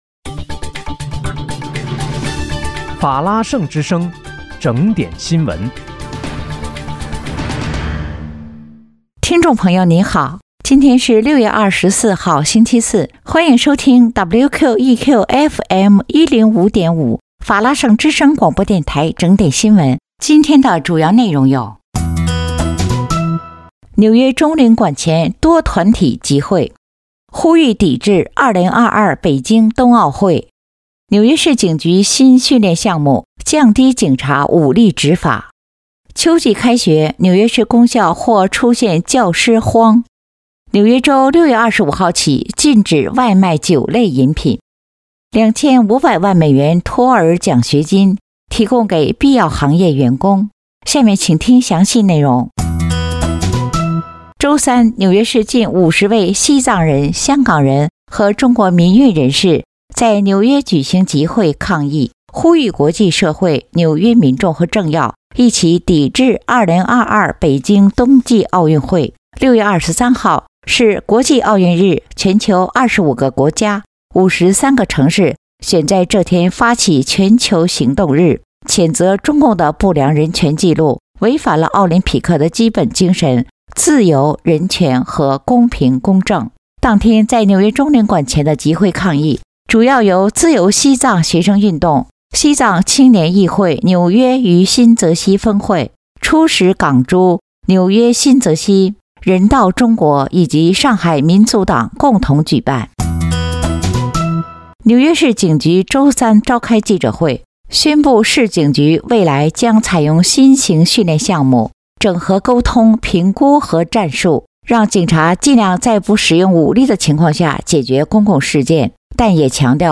6月24日（星期四）纽约整点新闻